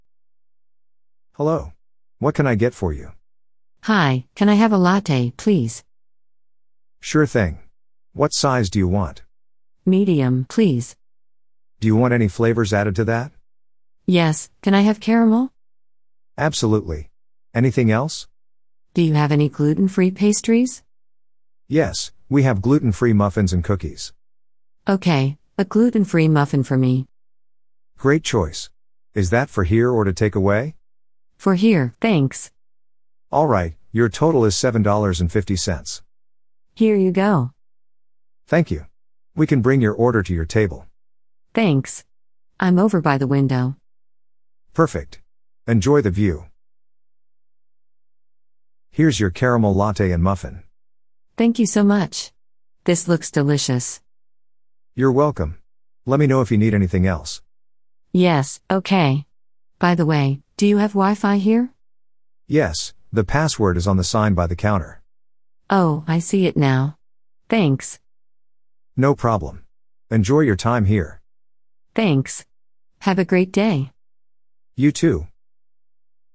Beginner Listening Practice
A customer orders a caramel latte and discusses options with a helpful barista.